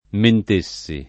[ ment %SS i ]